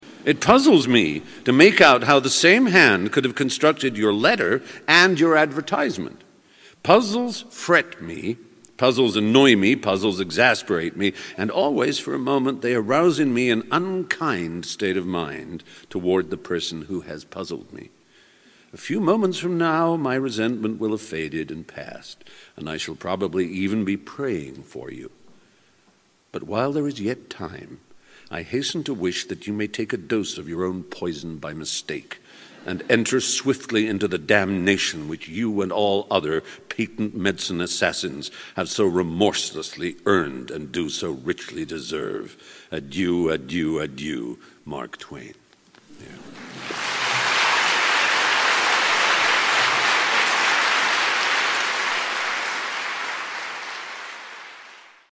MP3音频下载_第104期:史蒂芬·弗莱读信:马克·吐温的一封信(2)_可可英语